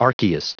Prononciation du mot archaist en anglais (fichier audio)
Prononciation du mot : archaist